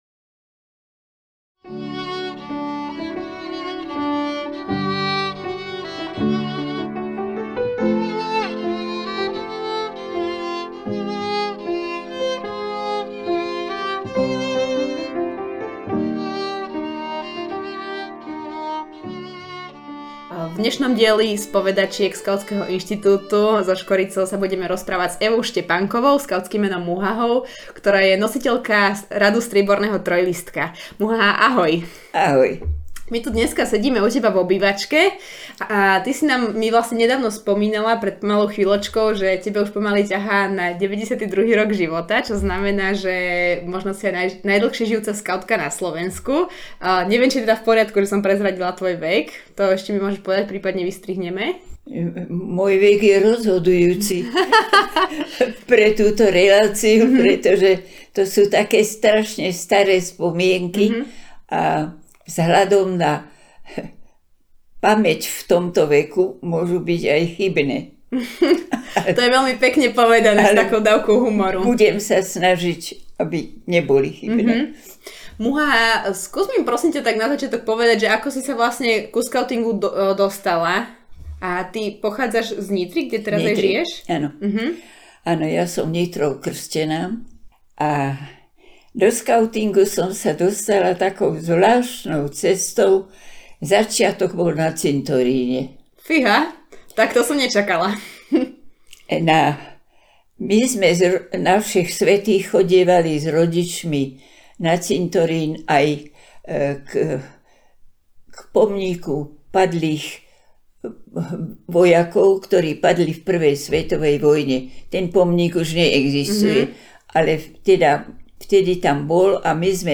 Diskusia o aktívnom občianstve a možnostiach, ako zapájať mladých ľudí do rozvoja komunít.